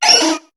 Cri de Grodoudou dans Pokémon HOME.